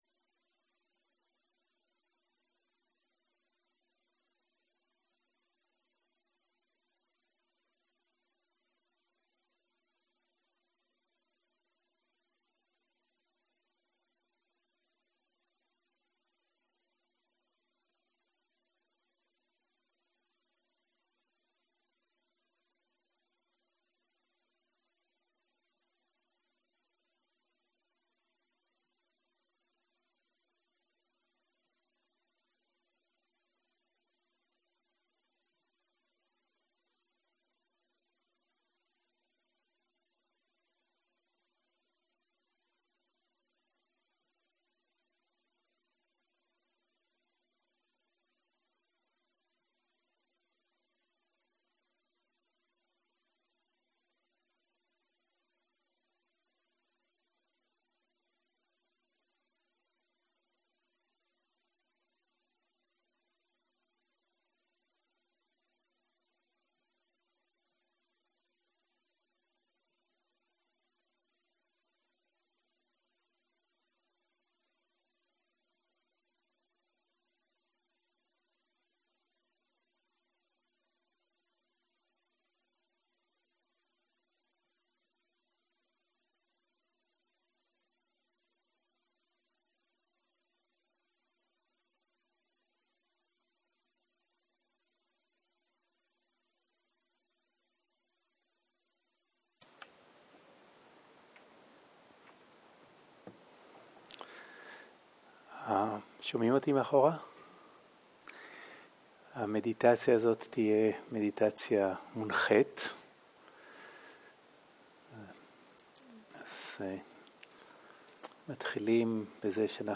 Guided meditation שפת ההקלטה